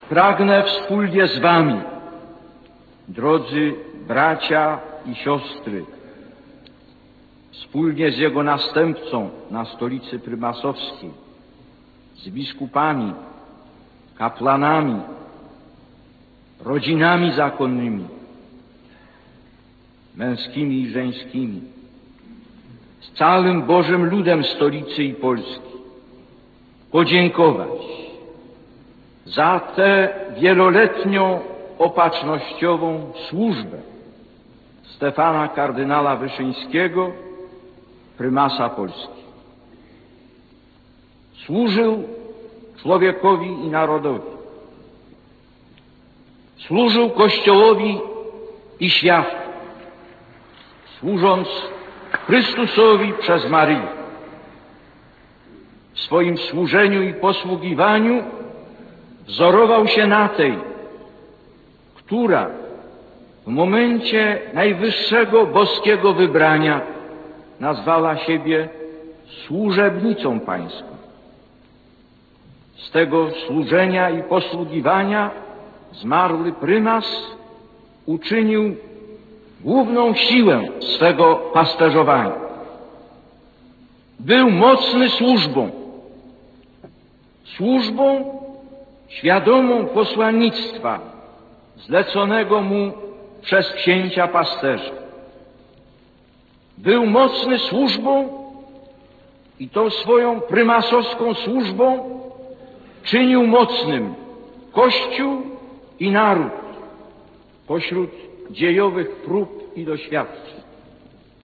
Prymas Tysiąclecia służbą czynił mocnym Kościół i naród - Fragment homilii wygłoszonej przez Jana Pawła II w katedrze św. Jana w Warszawie 16.06.1983.
homilia.ram